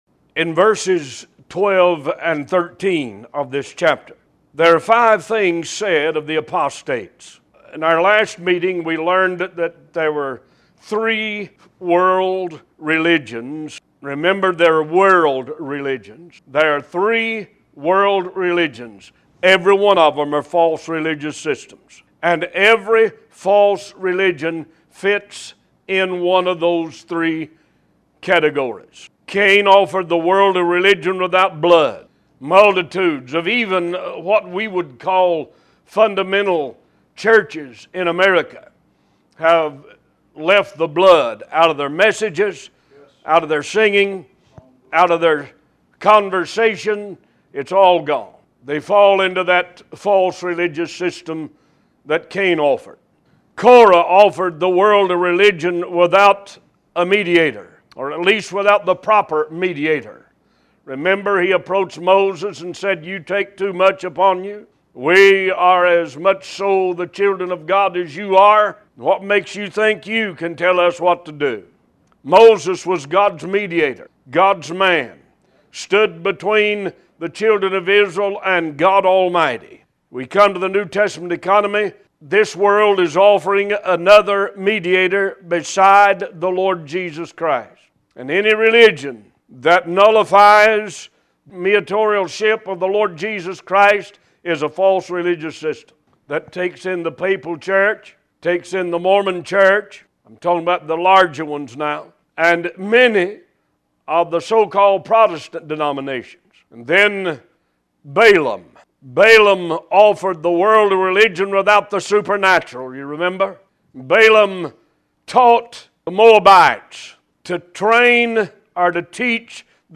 New Sermons published every Sunday and Wednesday at 11:30 AM EST